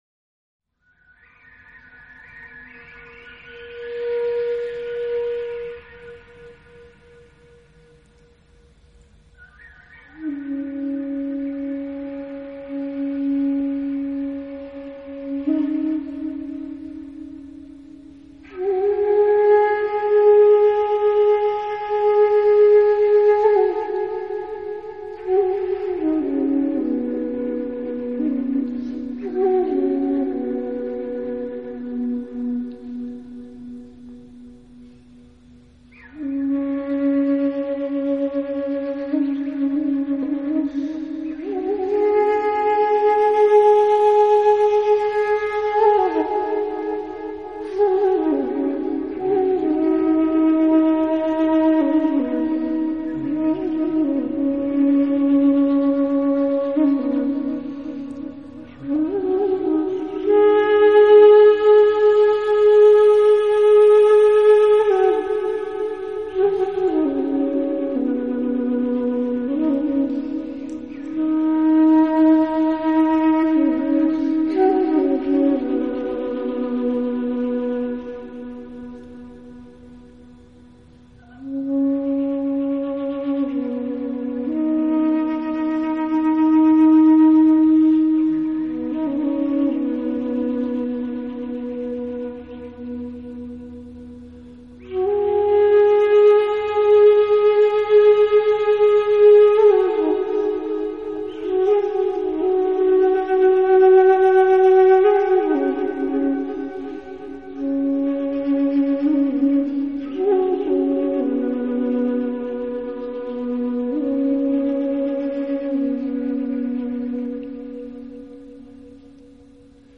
【纯音乐专辑】
藏笛吹响了，清远、嘹亮，自在、安恬。
藏笛的音色介乎于埙、笛之间；吹藏笛者是一位西藏的喇嘛。
作品几乎都是即兴而成——是随着心律的脉动而化生出来音律，因此犹如天籁。
同、同化共振，形成和谐共鸣，在唱和之中达成完美共识。